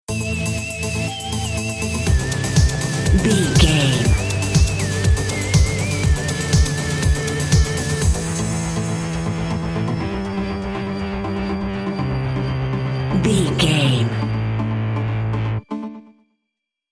Pop Electronic Dance Music 15 Sec.
Epic / Action
Fast paced
Aeolian/Minor
Fast
groovy
uplifting
energetic
bouncy
synthesiser
drum machine
house
techno
instrumentals
synth leads
synth bass
uptempo